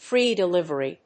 音節frèe delívery